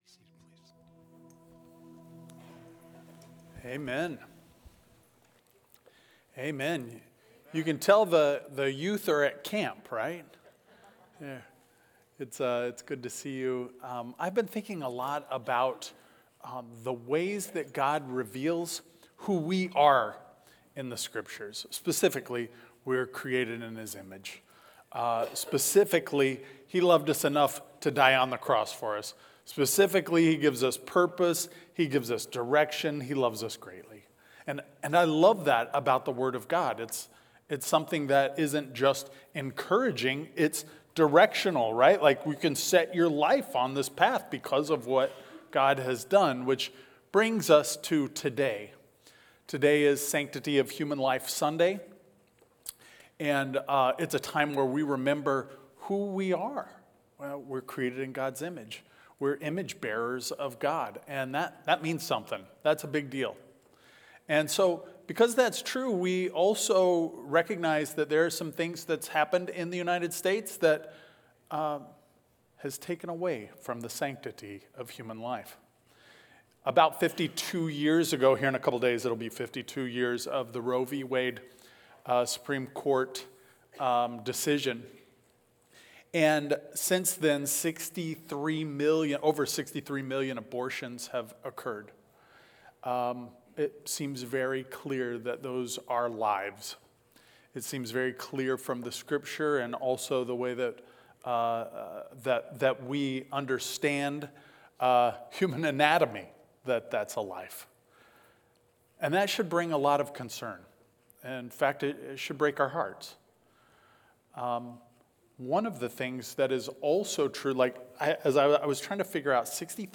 Listen to the Sunday morning messages given by the pastors of Friendship Church, Prior Lake and Shakopee, Minnesota.